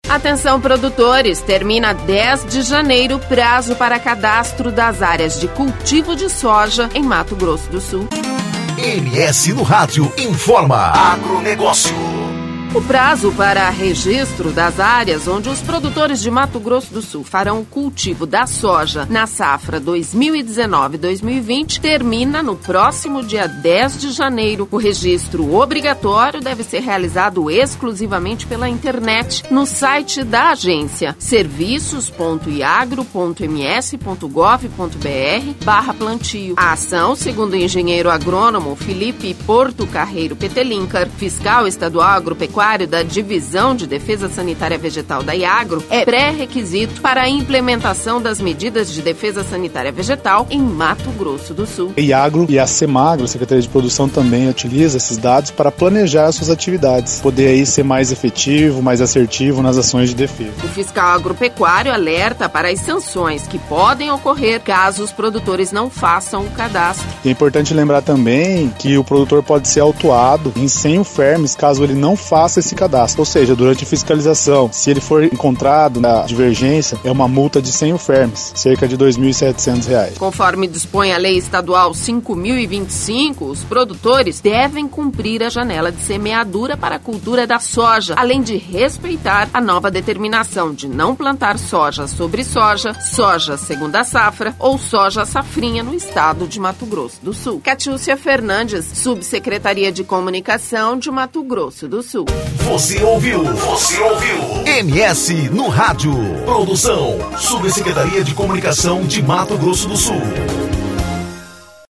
O fiscal agropecuário alerta para as sanções que podem ocorrer caso os produtores não façam o cadastro.